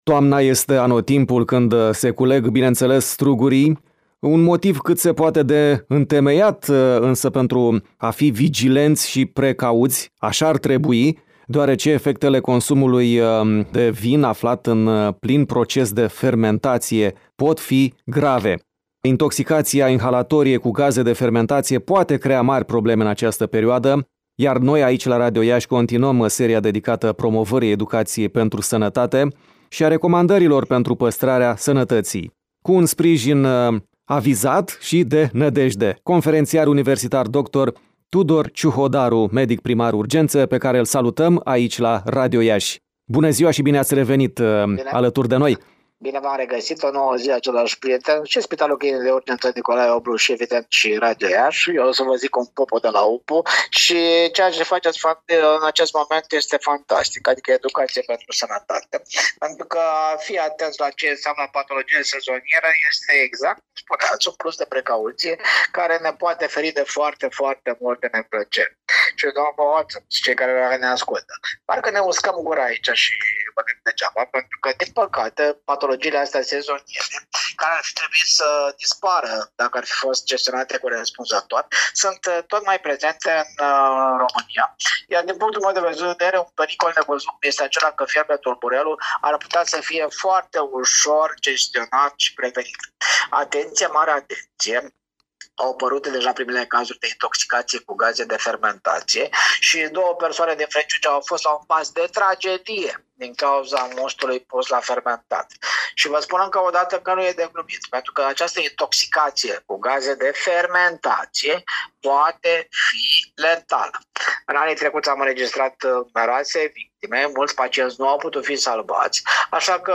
Pericol nevăzut: fierbe tulburelul! Conf.univ.dr. Tudor Ciuhodaru, medic primar urgențe